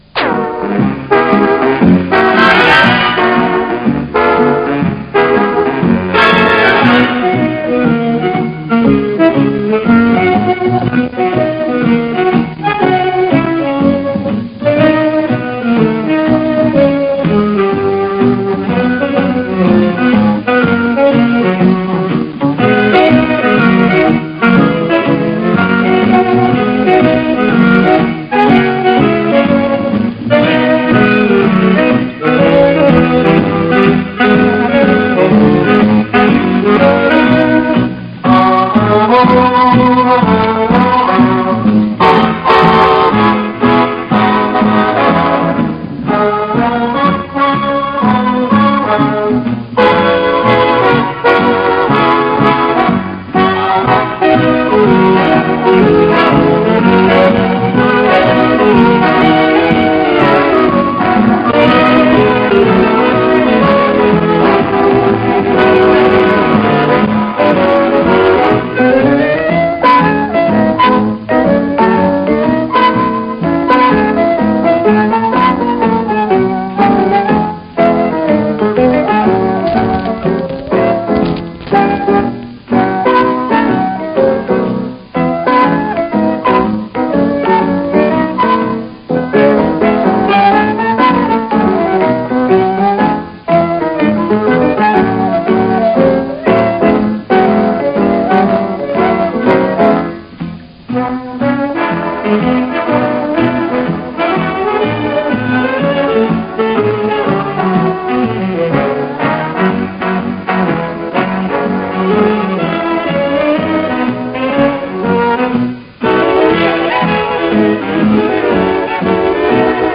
По большей части записи сделаны с КВ-радиостанции Лахти (кроме 13-16) в районе 1960 года (±2-3 года) и оцифрованы.
Неизвестный исполнитель – неопознанная композиция (инструментал).